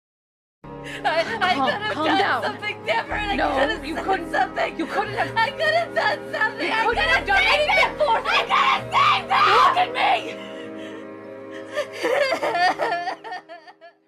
Reactions Soundboard2 views